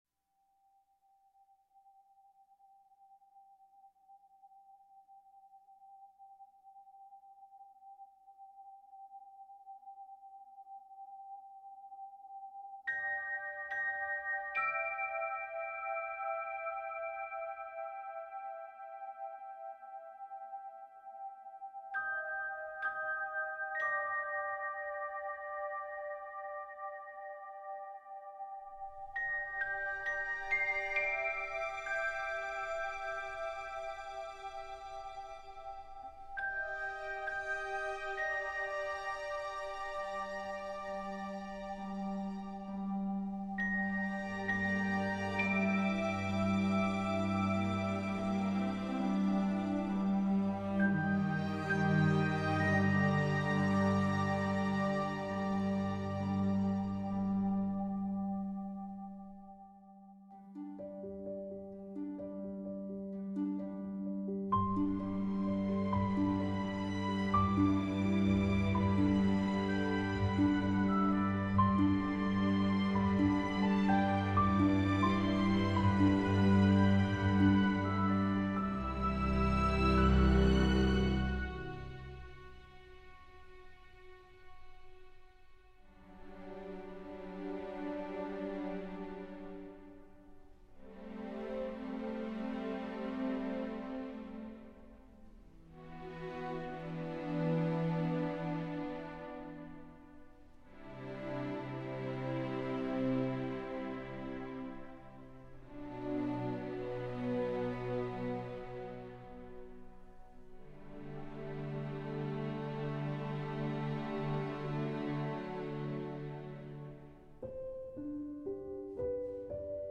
سبک موسیقی (Genre) موسیقی متن